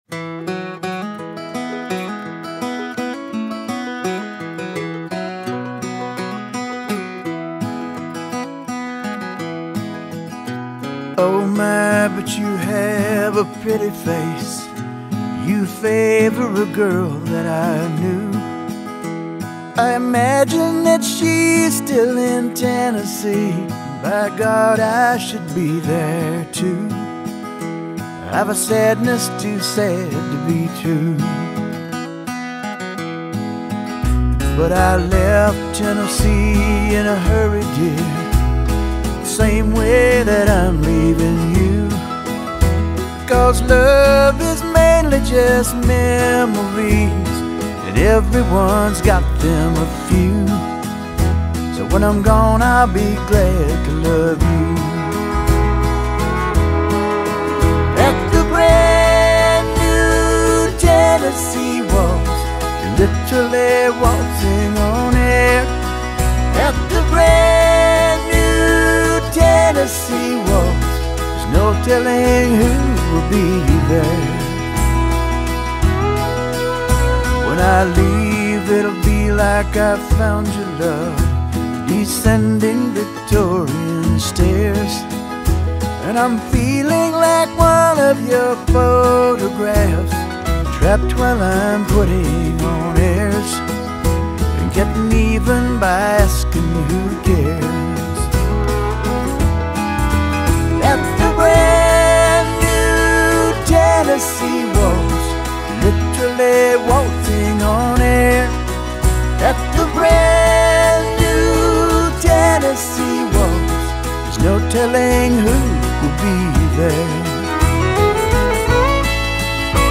vals